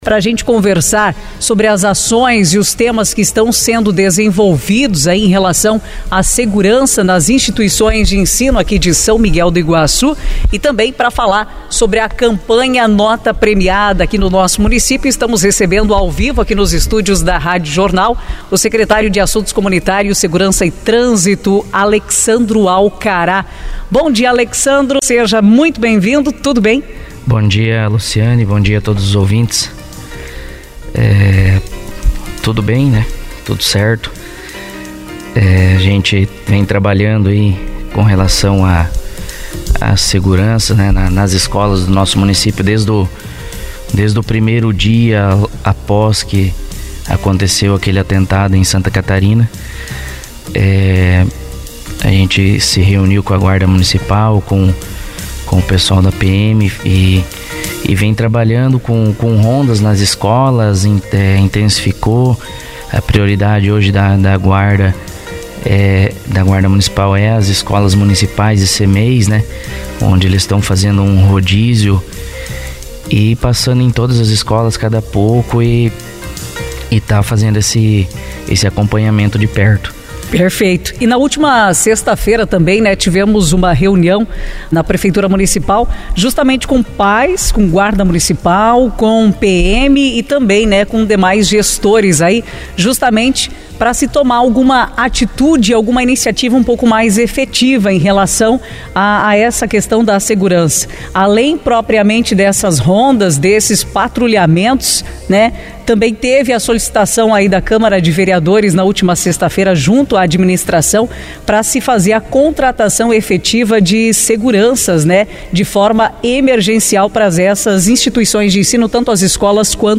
Na edição de hoje (18) do Jornal da Manhã Entrevista, recebemos o Secretário de Assuntos Comunitários, Segurança e Trânsito, Alexandro Alcará, para falar sobre a questão da Segurança nas Instituições de Ensino do município e da 3ª edição da Campanha Nota Fiscal Premiada.
entrevista-alexandro.mp3